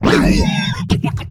alianhit2.ogg